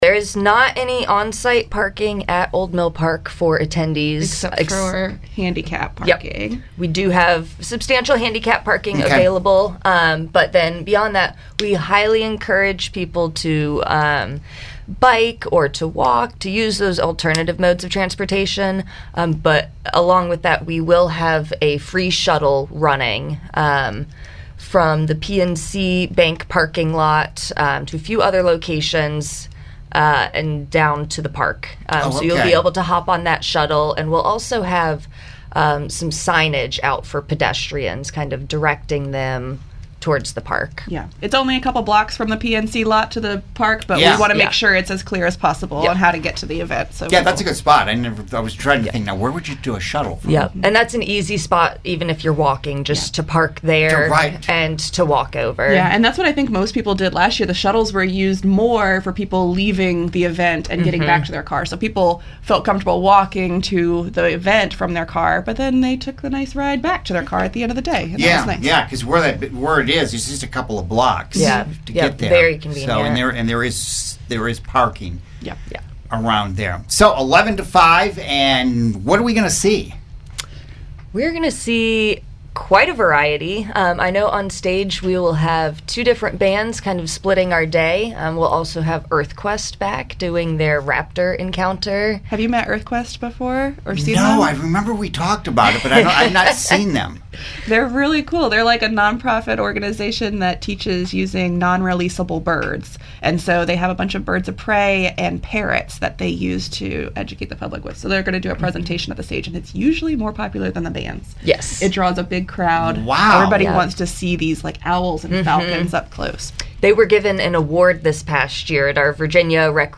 earth-day-preview.mp3